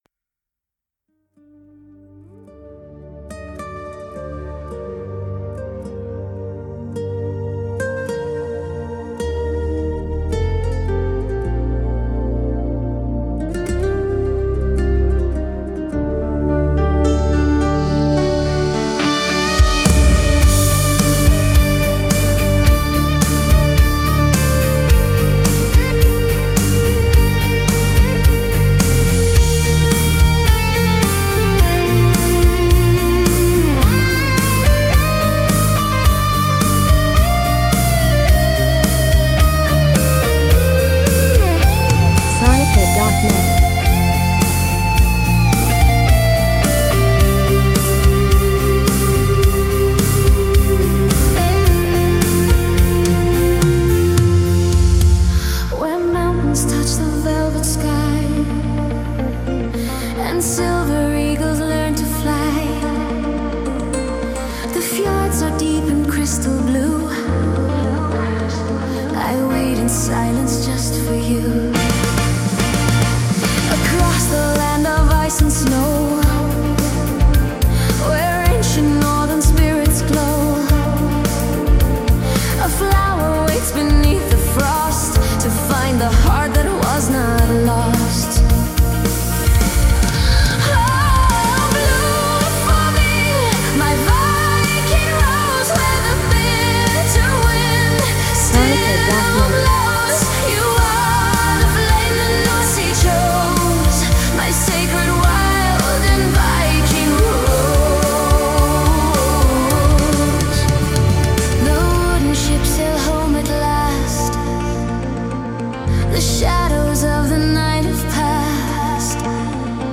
An Epic Glam Rock Odyssey